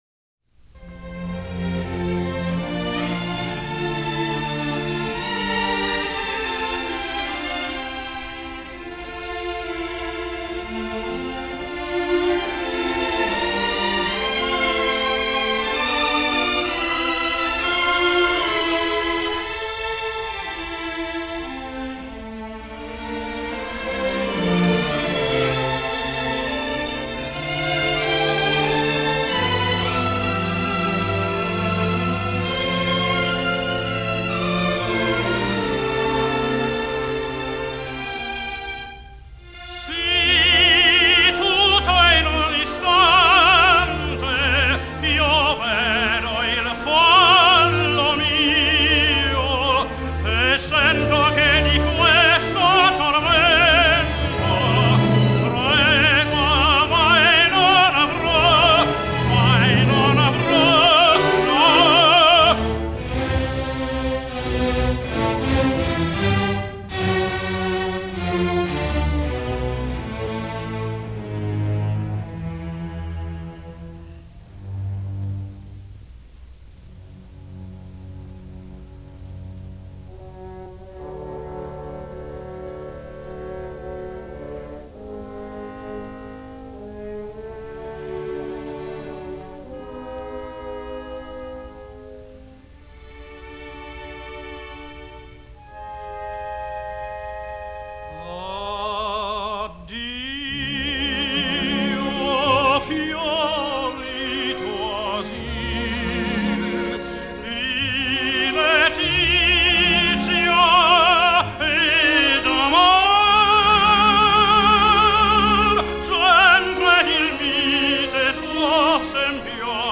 James Melton sings Madama Butterfly: